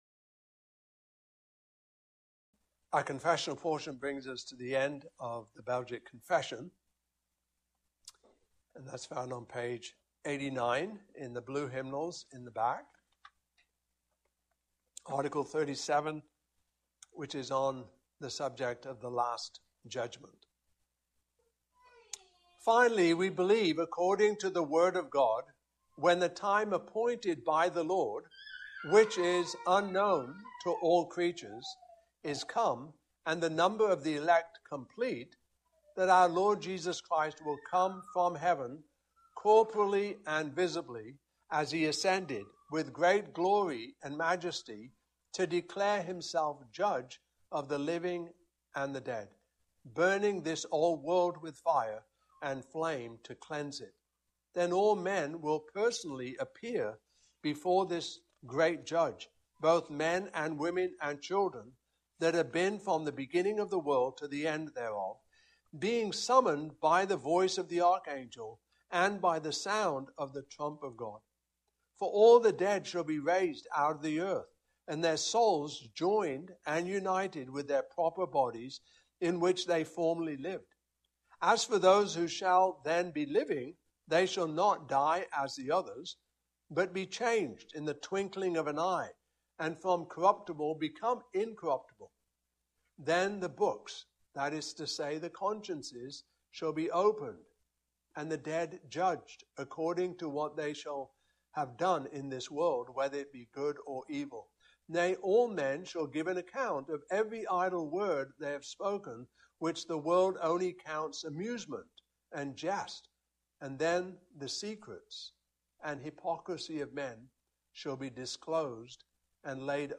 Revelation 11-15 Service Type: Evening Service Topics